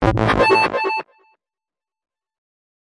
奇怪的合成器
描述：我在软音源上做的一个古怪的合成音符。
Tag: 电气 电子 合成器